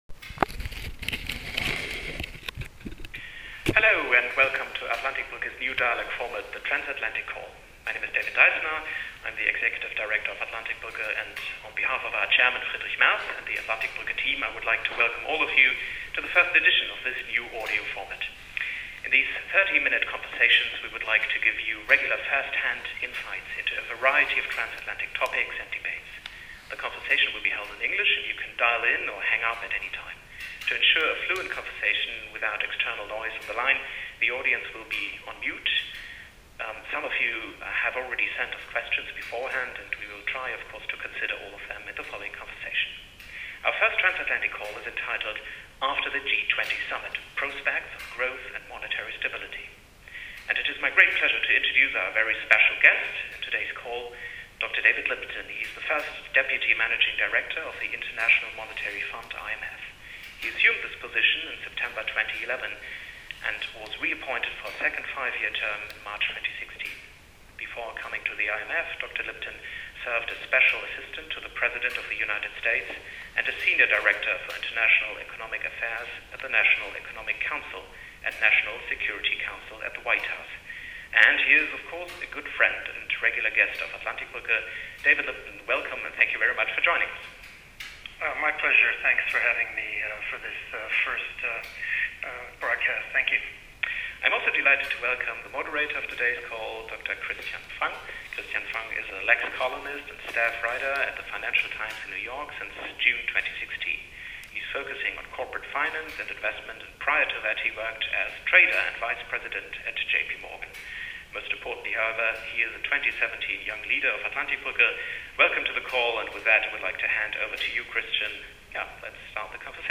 During their phone call they talked about the G20 and its new leaders on stage, international growth, the importance of free trade and the current situation of monetary stability.